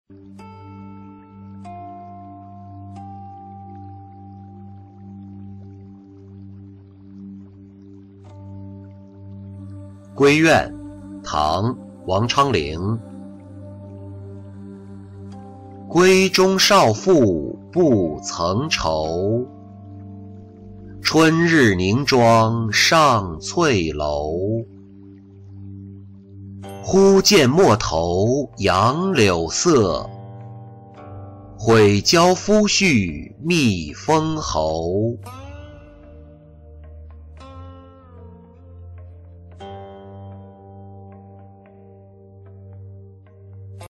闺怨-音频朗读